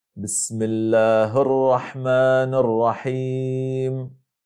b ـــ Att blanda dess ljud med en antydan av ljudet av en fathah, som i: